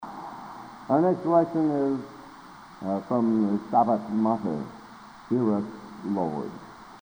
Collection: Broadway Methodist, 1980
Genre: | Type: Director intros, emceeing